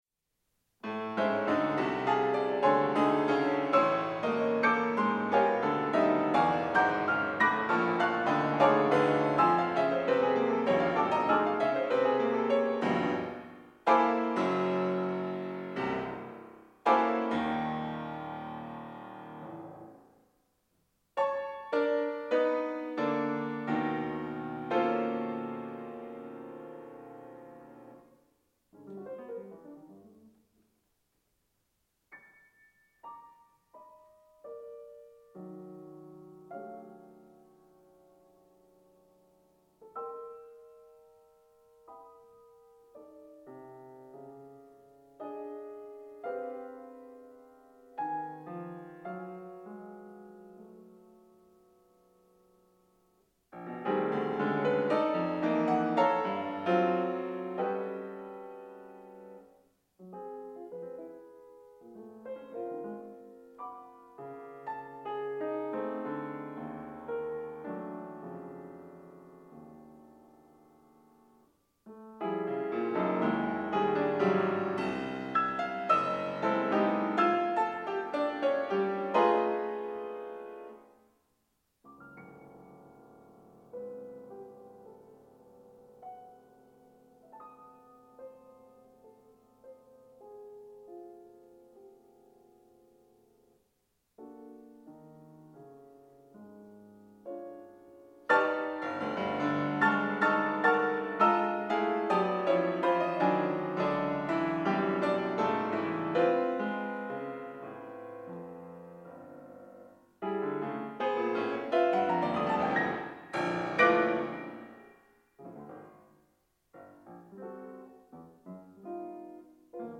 pianist Recorded in Britton Recital Hall at the University of Michigan School of Music, Theatre & Dance in 2010. 3:58 I